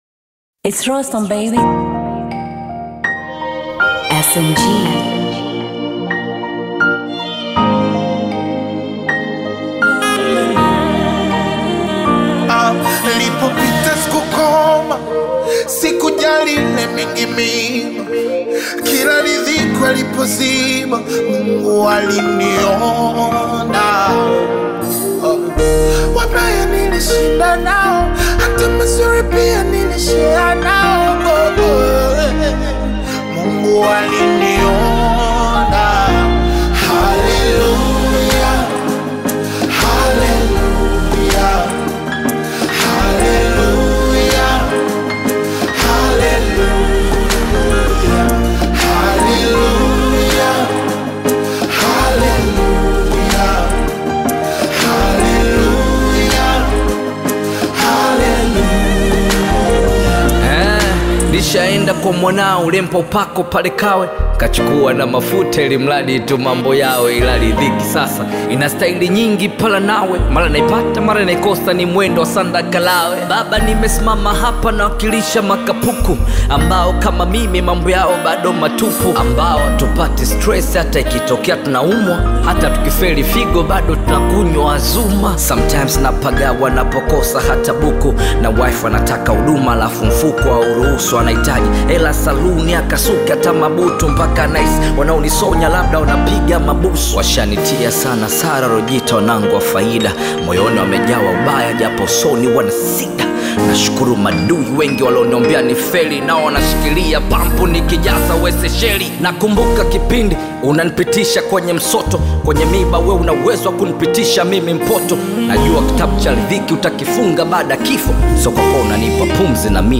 uplifting Tanzanian gospel/Afro-Pop collaboration